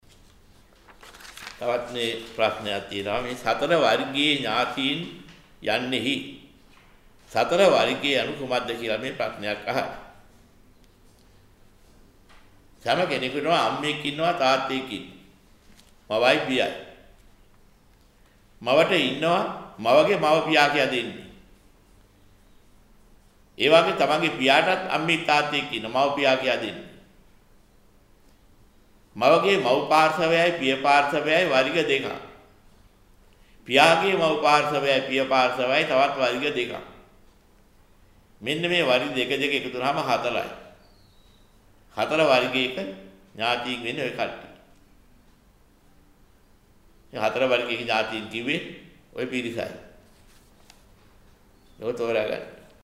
වෙනත් බ්‍රව්සරයක් භාවිතා කරන්නැයි යෝජනා කර සිටිමු 00:53 10 fast_rewind 10 fast_forward share බෙදාගන්න මෙම දේශනය පසුව සවන් දීමට අවැසි නම් මෙතැනින් බාගත කරන්න  (934 KB)